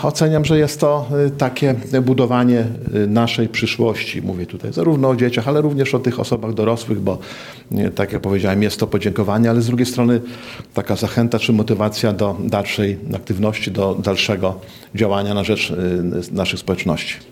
Osiem stypendiów i dwie nagrody starosty powiatu łomżyńskiego przyznano dzisiaj podczas specjalnej uroczystości w Regionalnym Ośrodku Kultury.